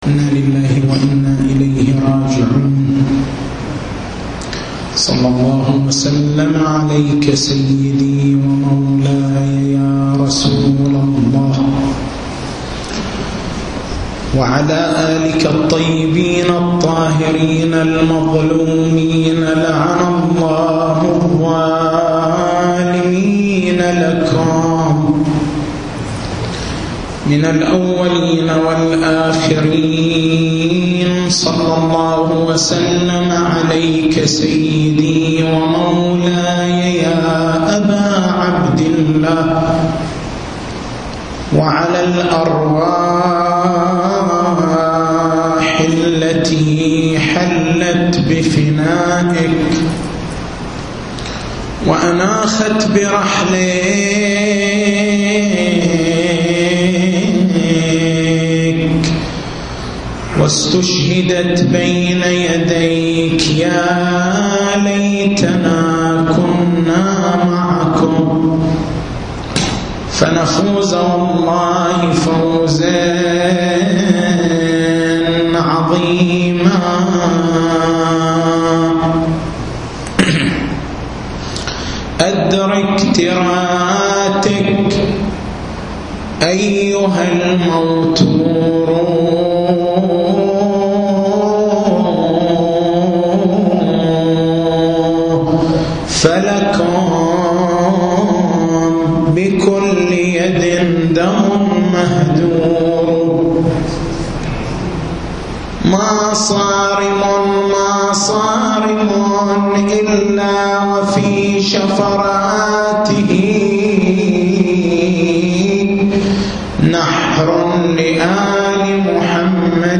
تاريخ المحاضرة: 28/09/1430 نقاط البحث: وقفة مع آية {يا مريم إن الله اصطفاك وطهرك} هل تخالف روايات الأفضلية هذه الآية؟ وقفة مع حديث (لولا علي لما كان لفاطمة كفء) هل يدل هذا الحديث على أفضليتها على الأنبياء؟